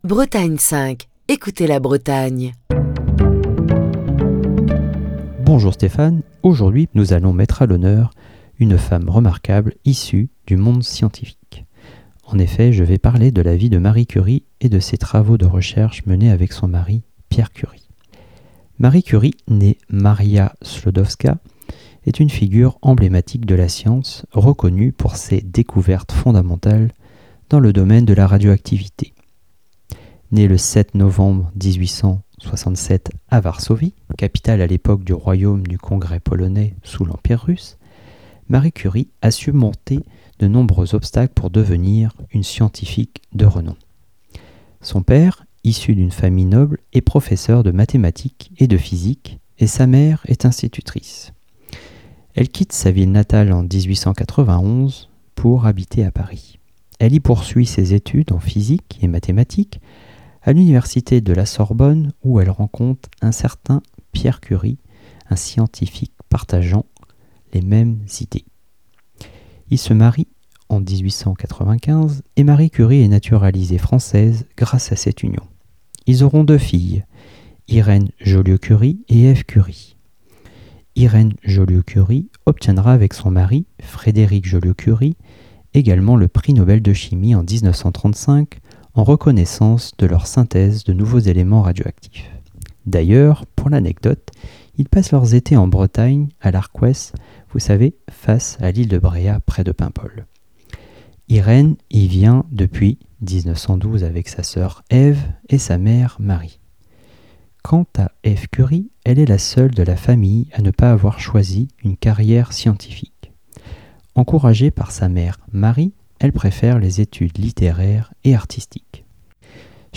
Chronique du 3 avril 2024.